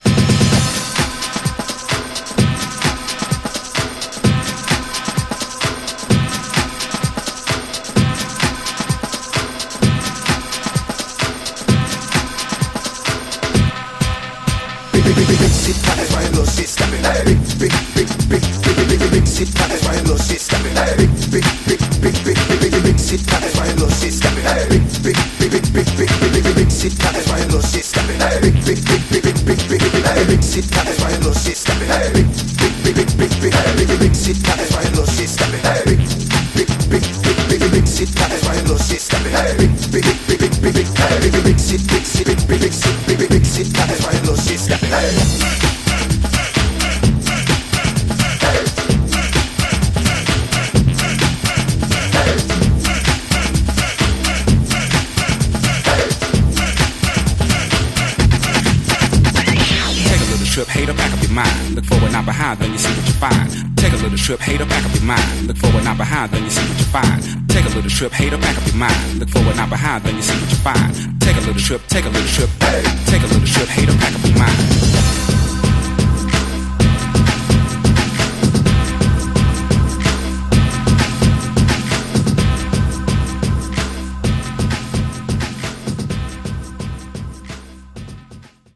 130 bpm
Dirty Version